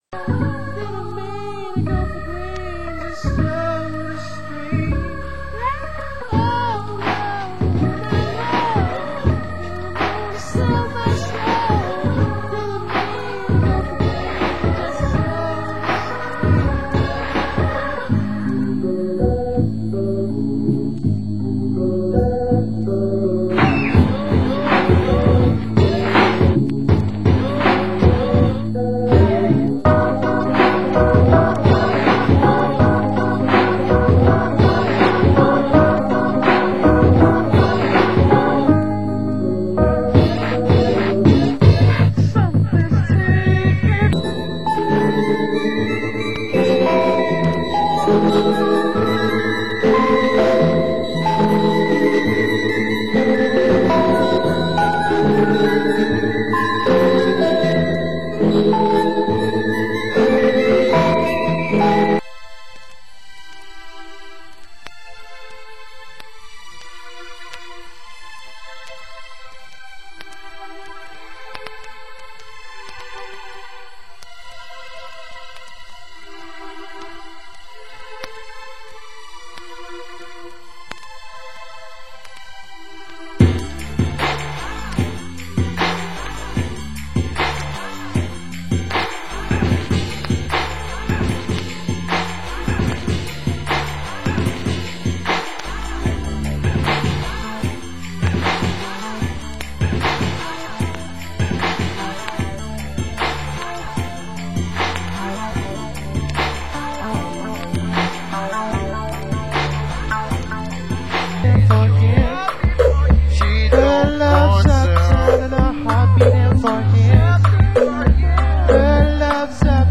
Genre: Synth Pop
Genre: Experimental